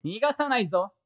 戦闘 バトル ボイス 声素材 – Battle Voice